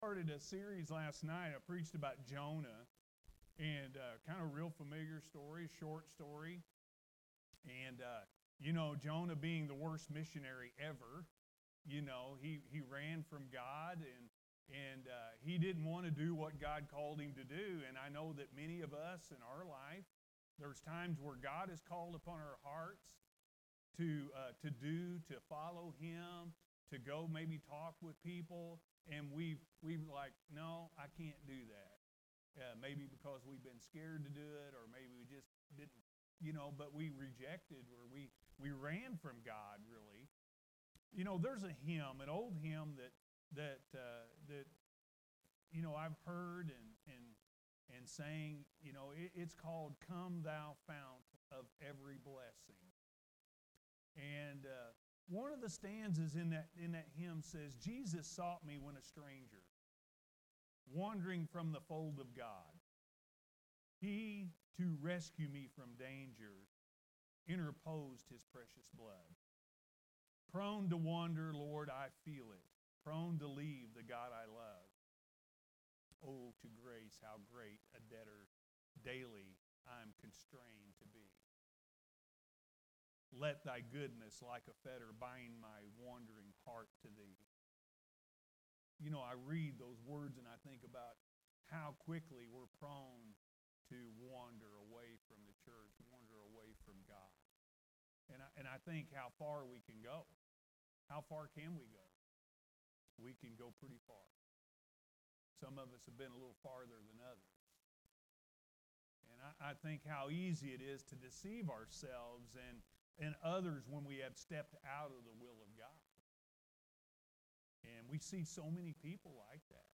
You Must Die To Live-A.M. Service